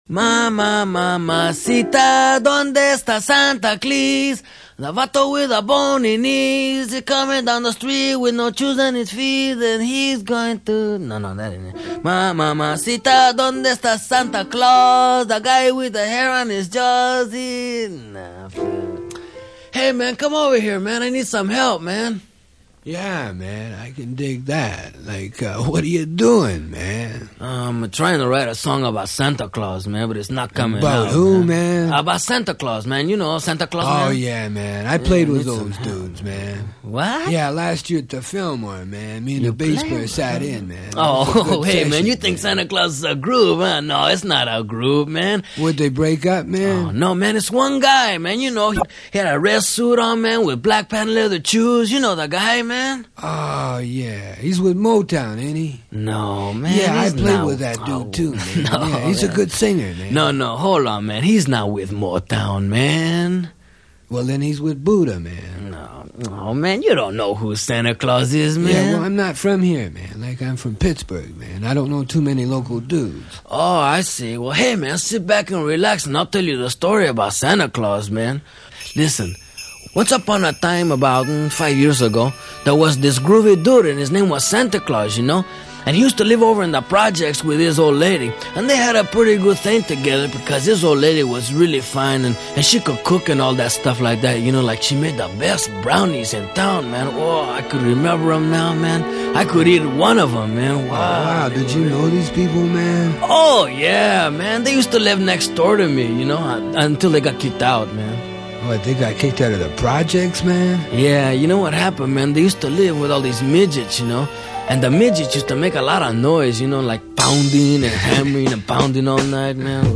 Das kultige US-Comedy-Duo Cheech & Chong mit seinem Sketch 'Dave' und dem legendären 'Santa Clause'.